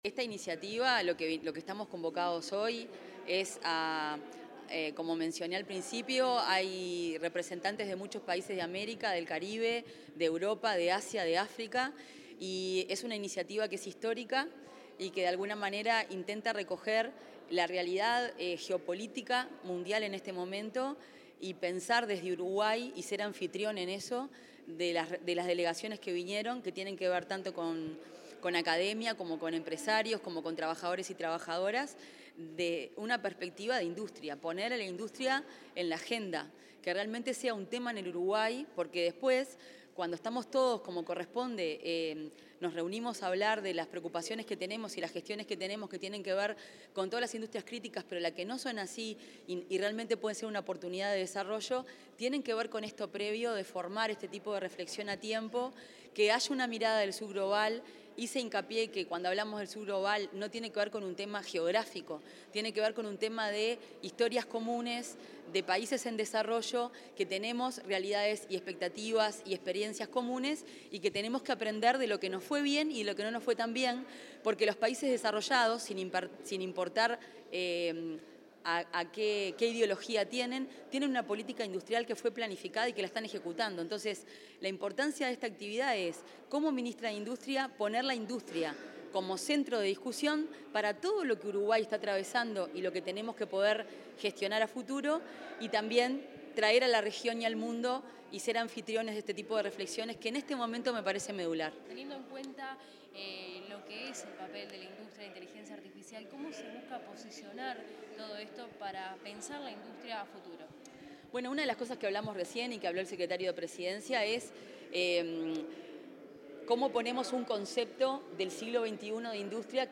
Declaraciones de la ministra de Industria, Fernanda Cardona
Declaraciones de la ministra de Industria, Fernanda Cardona 08/10/2025 Compartir Facebook X Copiar enlace WhatsApp LinkedIn Tras participar en la apertura de un encuentro sobre política industrial y finanzas, la ministra de Industria, Energía y Minería, Fernanda Cardona, efectuó declaraciones a los medios informativos acerca del alcance de la actividad y el futuro del sector.